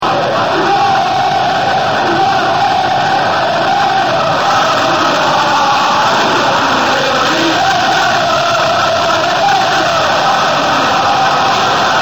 Roma, Stadio Olimpico,